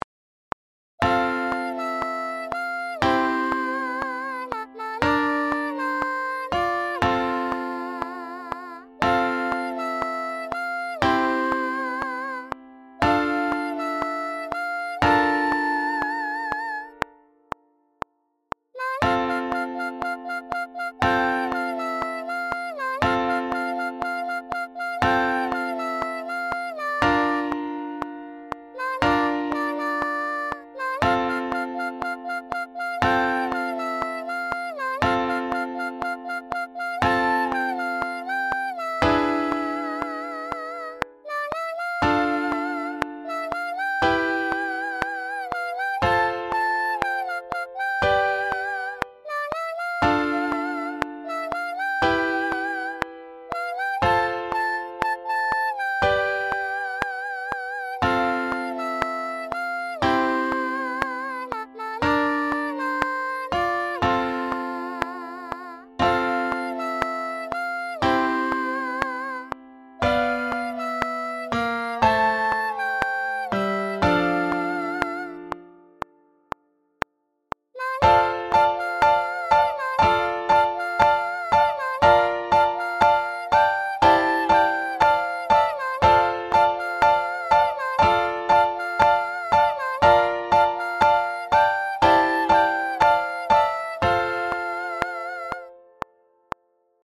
LogicでMIDIファイルを読み込んで、 歌うということでは大丈夫。